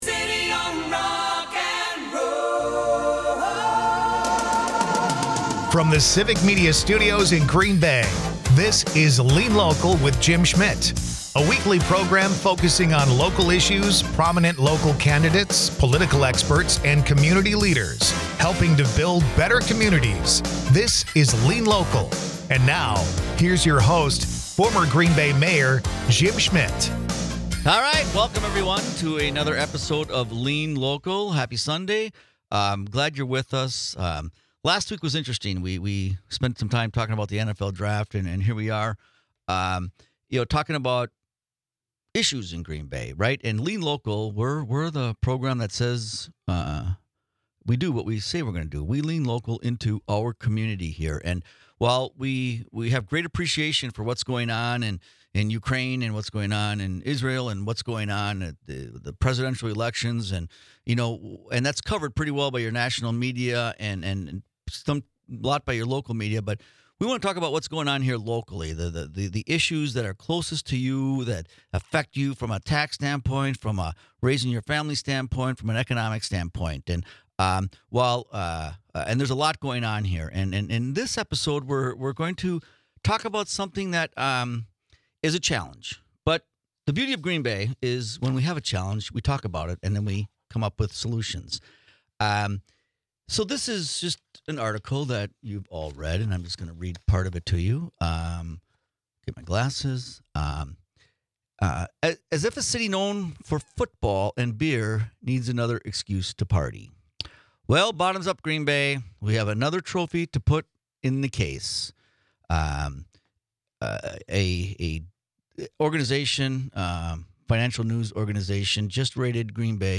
Lean Local is a part of the Civic Media radio network and airs Sunday's from 1-2 PM on WGBW .
Dive into the heart of community issues with 'Lean Local,' hosted by former Green Bay Mayor Jim Schmitt.
Instead, it 'leans local' with insightful discussions and grassroots solutions, focusing on what truly matters in our neighborhoods and communities.